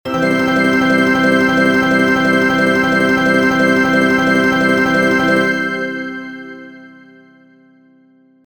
特急
接近メロディー(特急).mp3